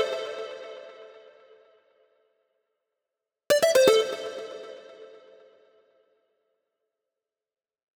23 MonoSynth PT4.wav